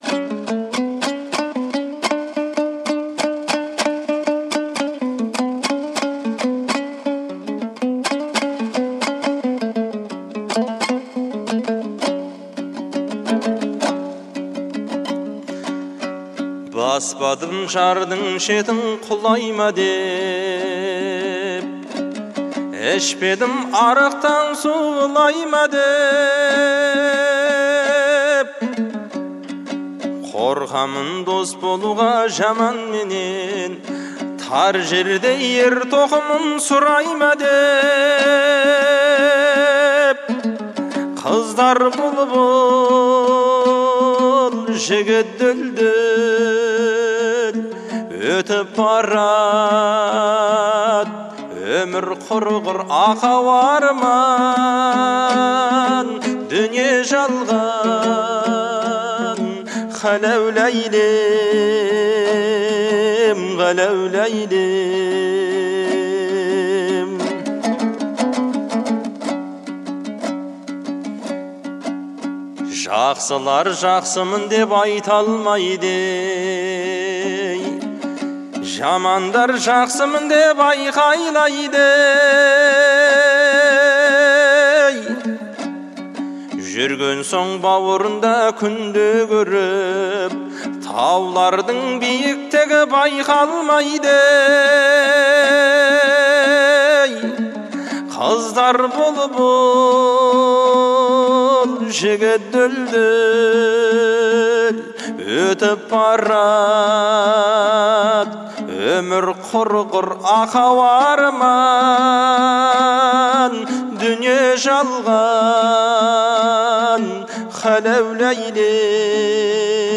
мощный и выразительный вокал, который проникает в душу